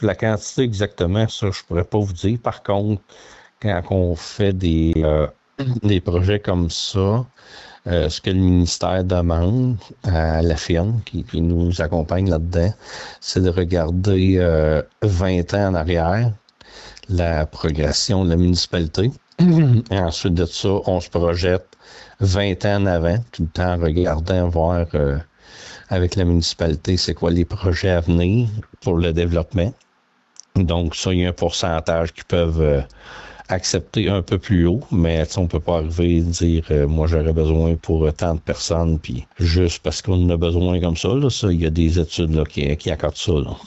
Le maire, Laurent Marcotte, a expliqué le processus d’évaluation dans ce dossier.